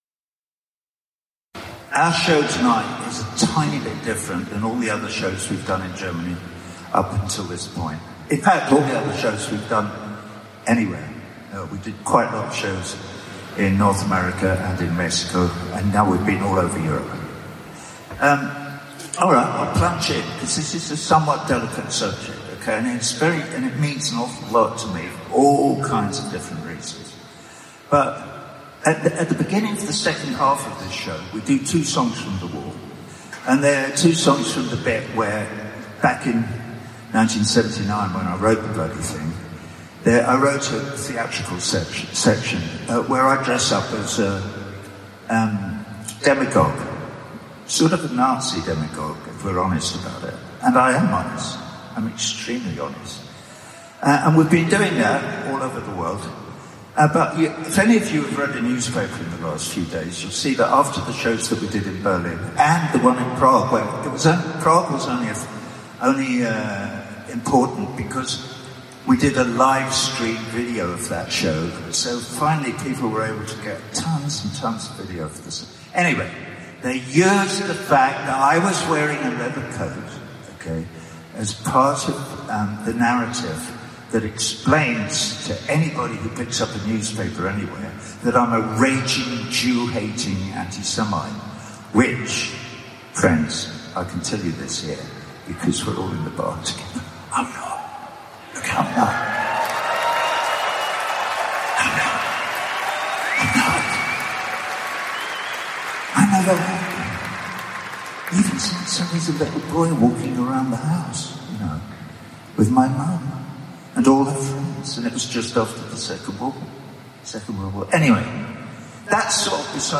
Roger Waters Frankfurt Thanks Speech Video